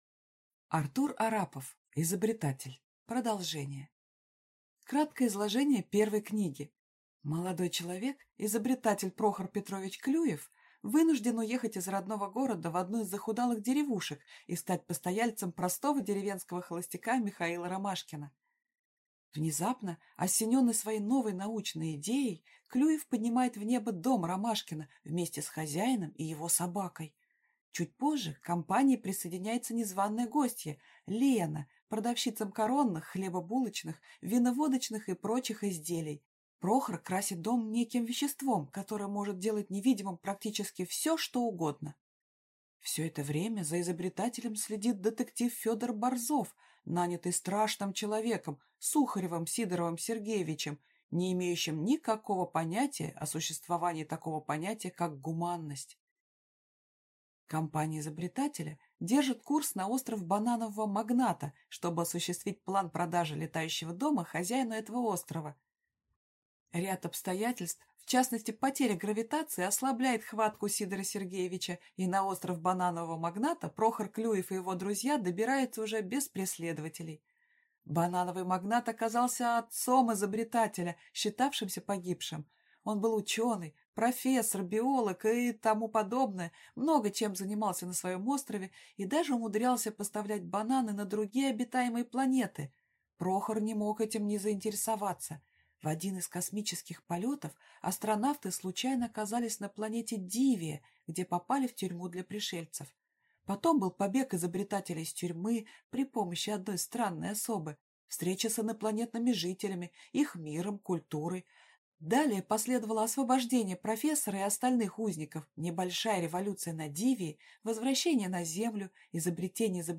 Аудиокнига Изобретатель. Продолжение | Библиотека аудиокниг